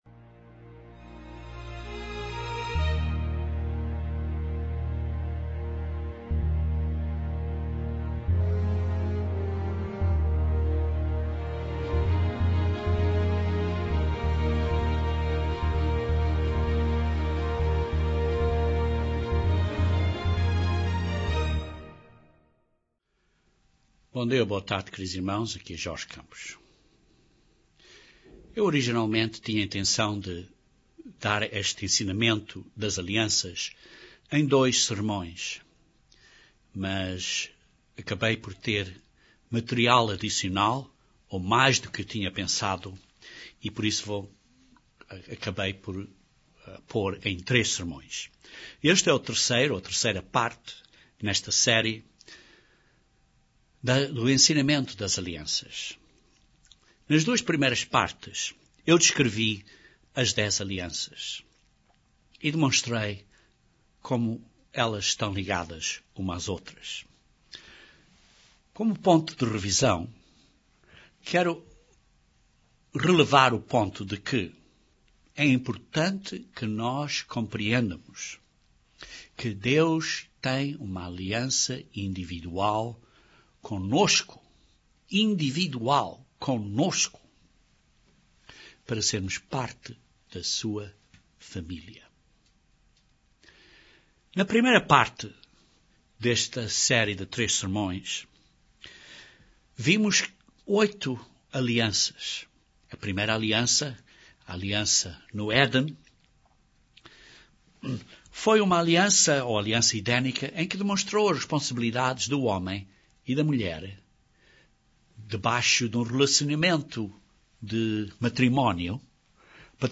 Para entendermos melhor a Nova Aliança é importante entendermos a estrutura das Alianças de Deus. Este terceiro sermão descreve como Paulo entendeu a Nova Aliança e analisamos cuidadosamente como é que Deus tem uma Aliança consigo individualmente para você ser parte de Sua Família.